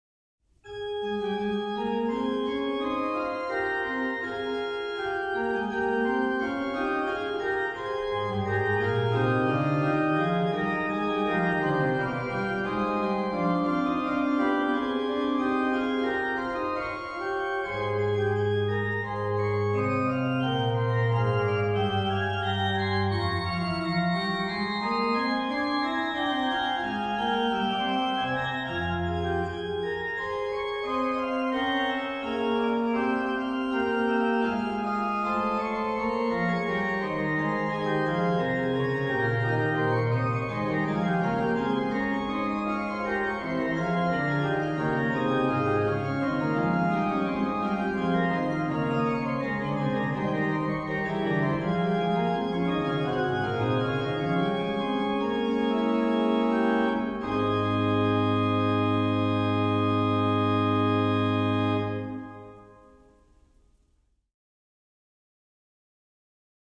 Subtitle   Fughetta; manualiter
Registration   Ged8, Rfl4, Oct2